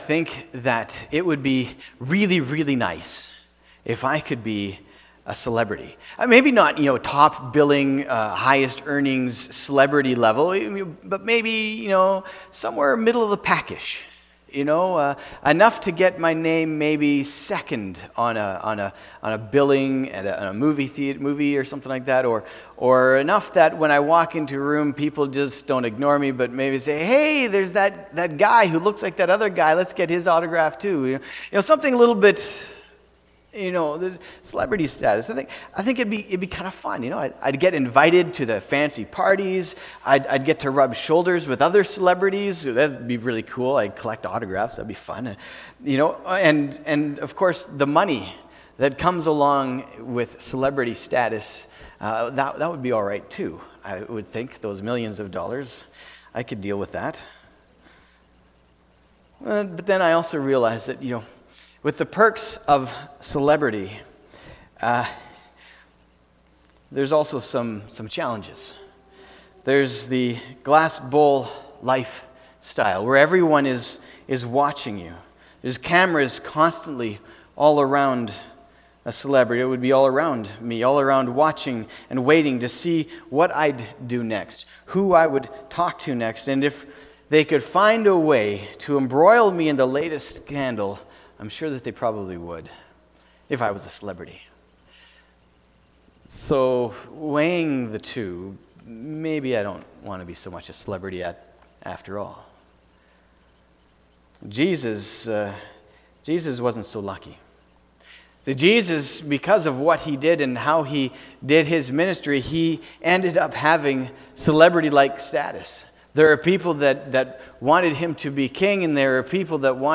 sept-1-2019-sermon.mp3